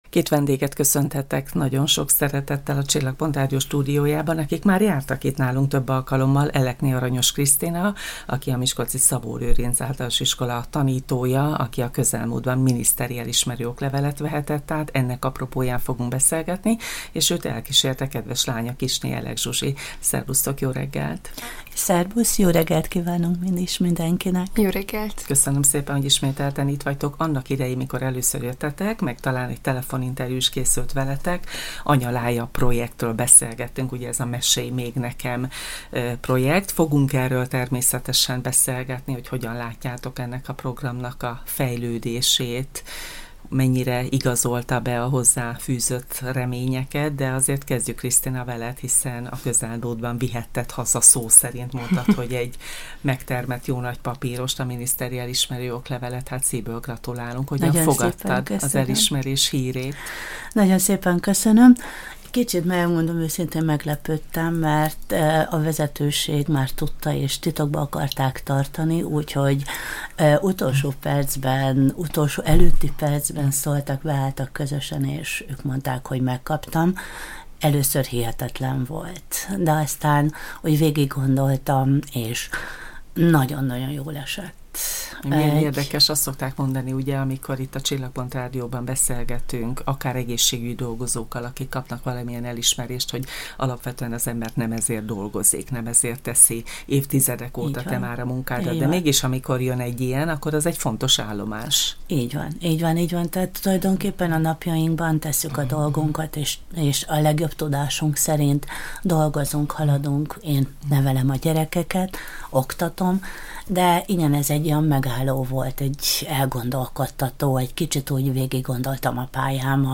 Anya és lánya közös projektjükről is mesélt a Csillagpont Rádió kedd reggeli közéleti magazinműsorában.